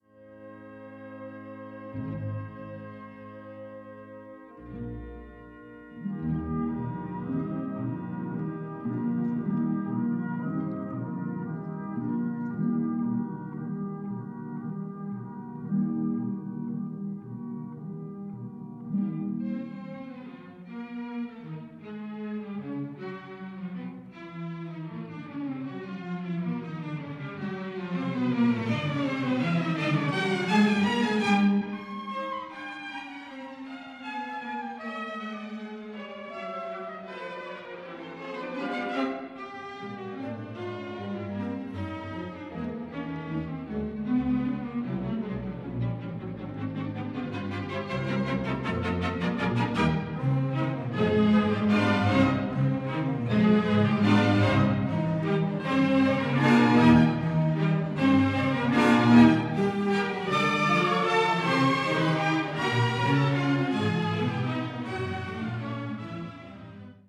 This is a stereo recording